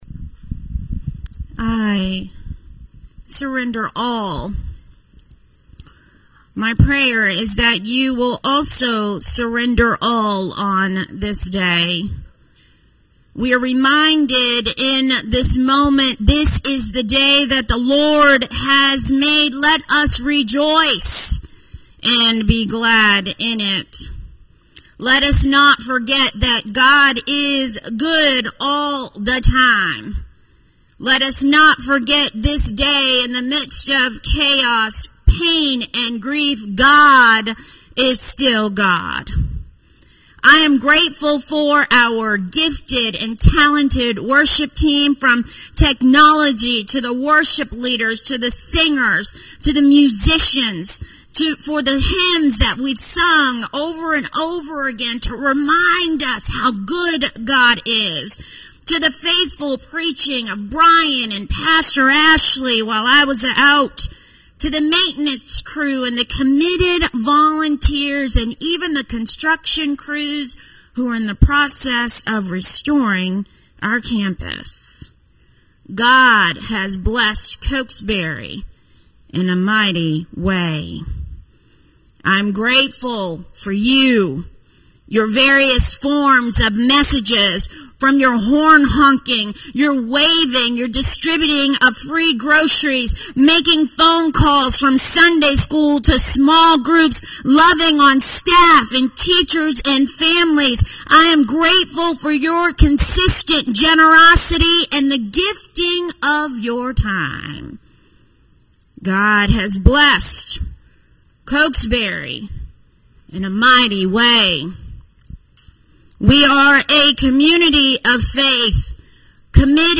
1-10-Sermon.mp3